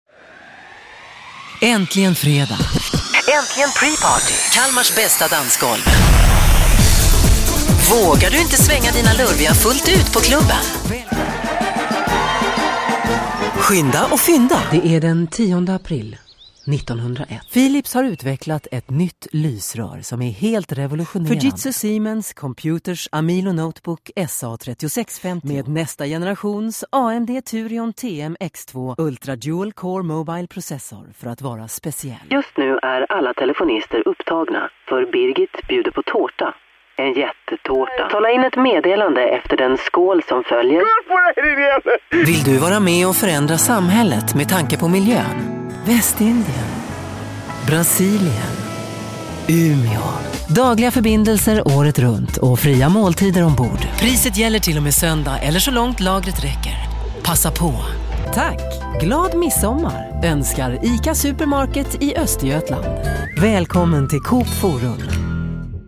Schwedische weibliche stimme, Schwedischer VO, profi,
Sprechprobe: Industrie (Muttersprache):